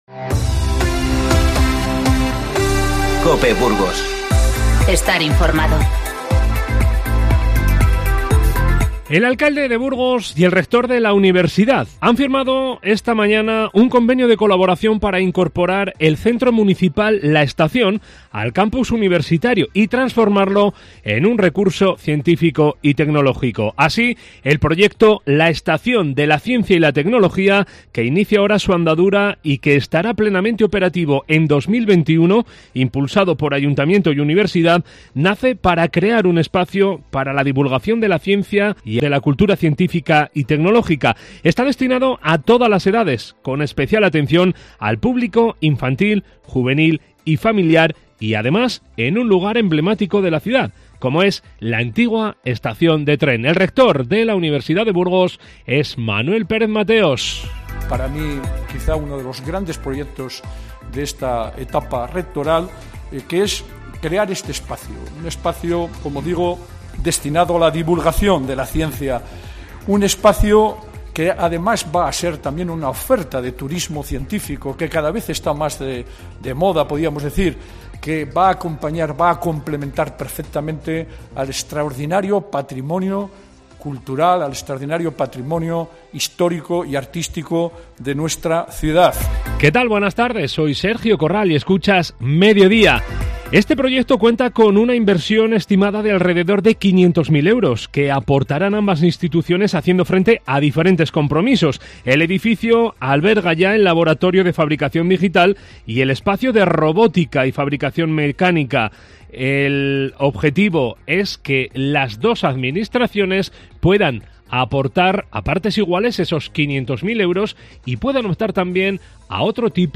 INFORMATIVO 14-01-20